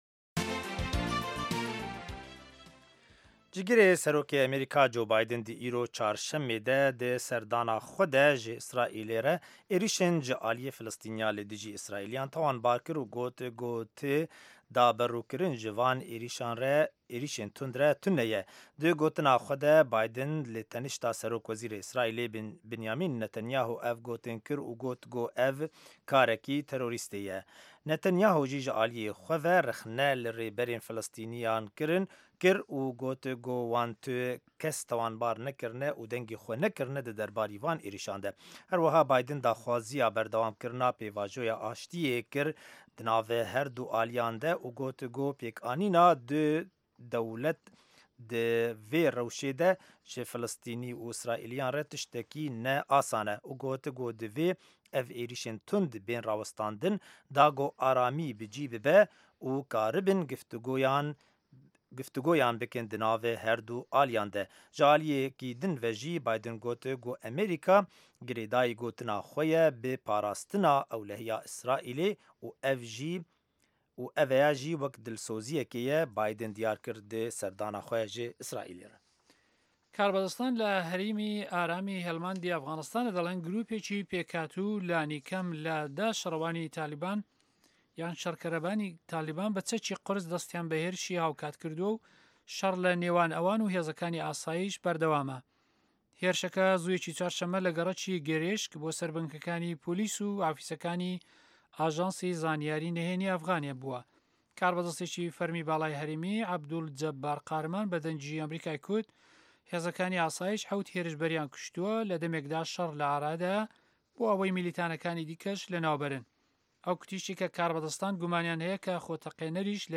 هه‌واڵه‌کان، ڕاپـۆرت، وتووێژ، سه‌رگوتاری ڕۆژانه‌‌ که‌ تیایدا ڕاوبۆچوونی حکومه‌تی ئه‌مه‌ریکا ده‌خرێته‌ ڕوو.